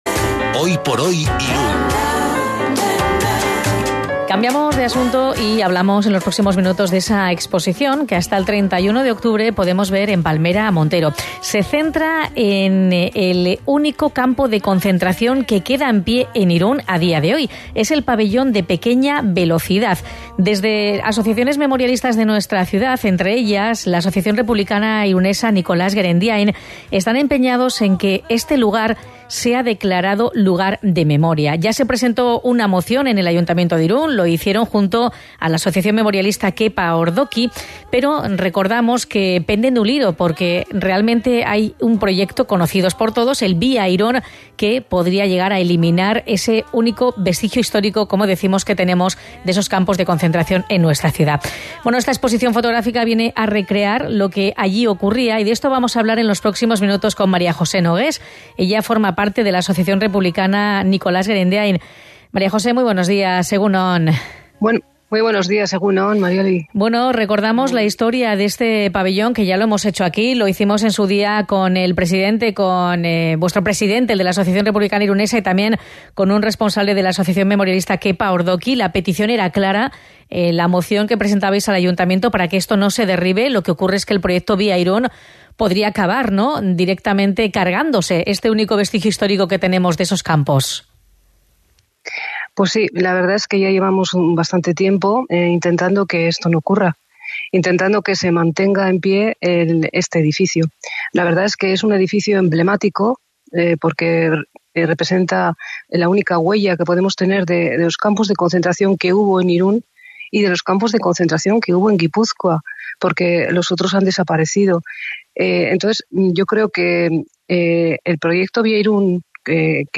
Informazio gehiago Deskargatu kartela Elkarrizketa Radio Irun Cadena Ser irratian. 2023-10-17 Erakusketaren bideo eta argazkiak